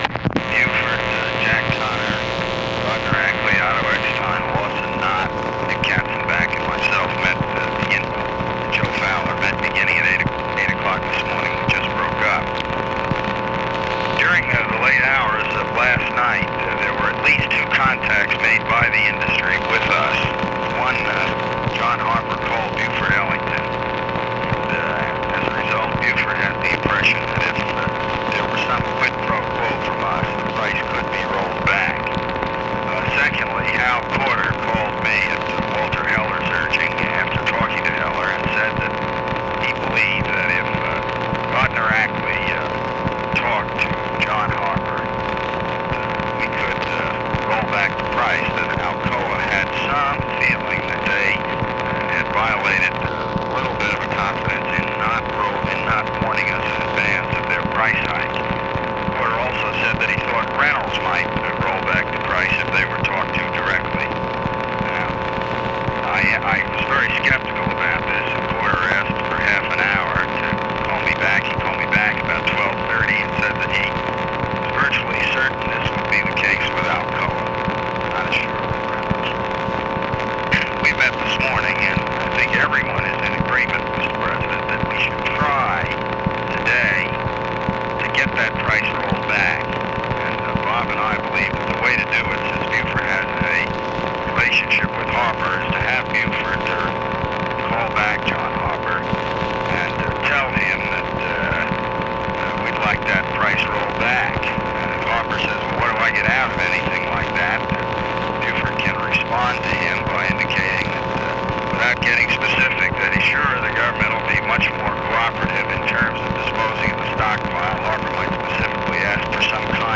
Conversation with JOSEPH CALIFANO and ROBERT MCNAMARA, November 8, 1965
Secret White House Tapes